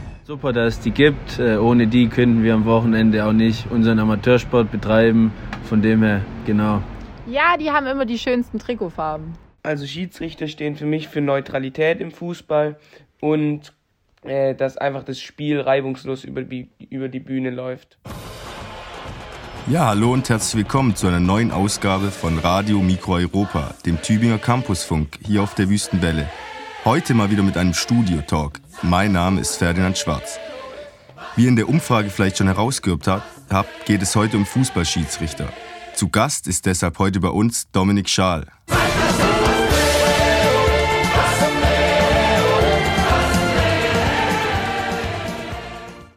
Moderation
Studiogast